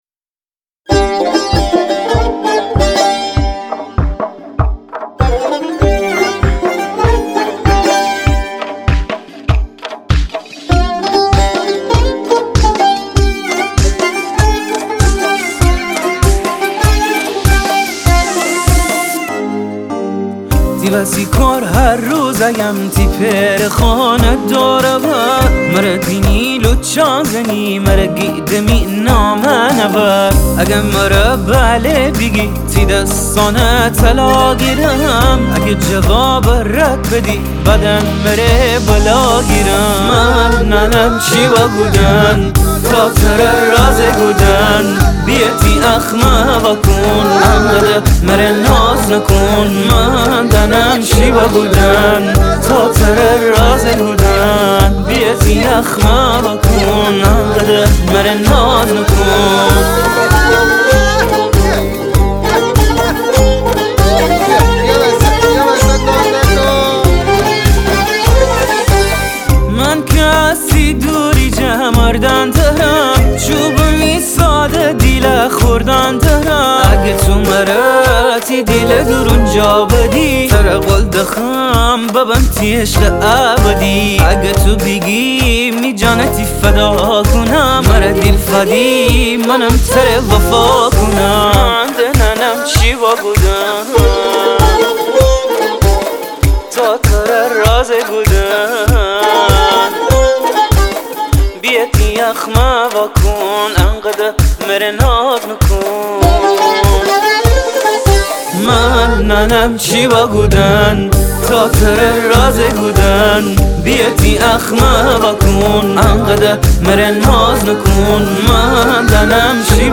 اهنگ جدید شمالی اهنگ شاد شمالی